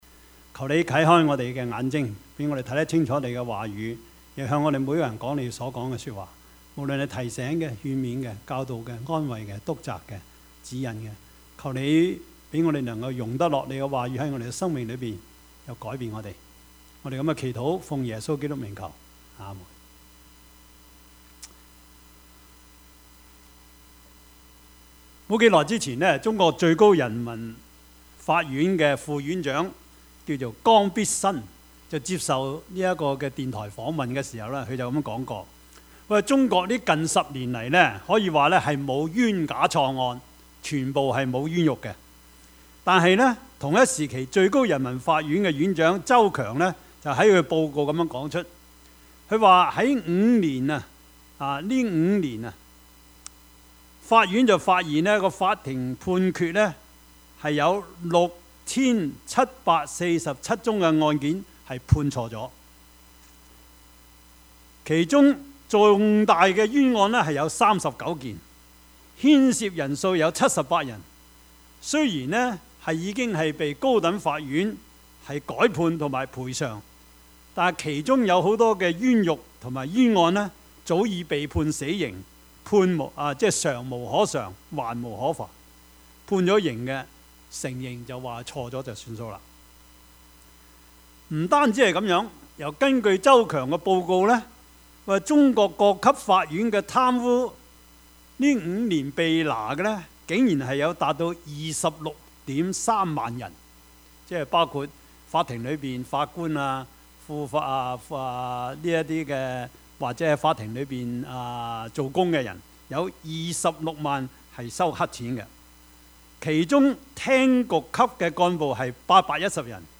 Service Type: 主日崇拜
Topics: 主日證道 « 視而不見 跟隨基督的意義 »